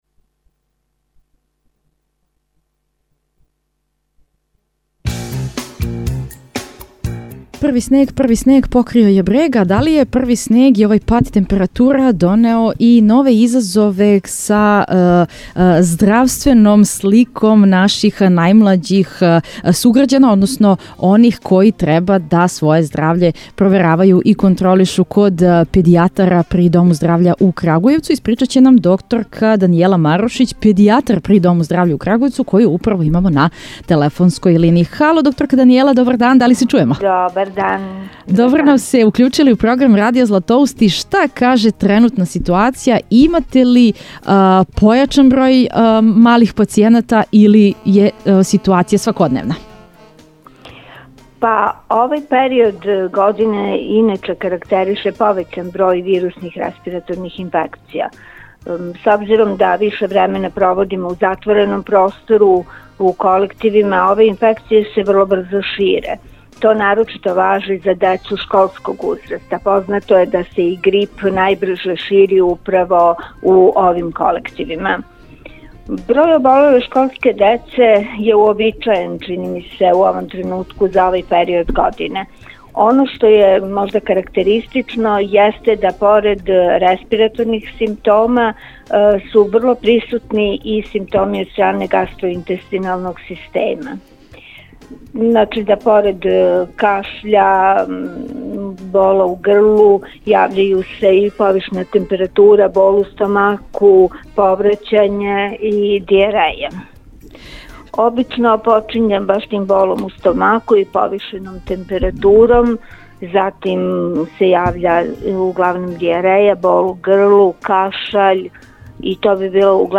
Код деце која ових дана траже помоћ свог изабраног педијатра најчешће су присутне респираторне и гастроинтестиналне тегобе. Како се ови здравствени изазови лече и када је време да је деца јаве педијатру, сазнајемо у разговору са нашом саговорницом.